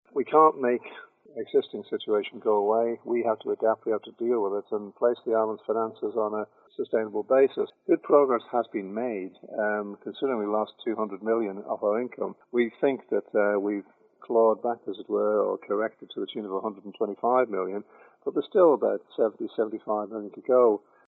That's the Treasury Minister who's certain the Island is on the right track - however he says politicians elected next year 'must do better'.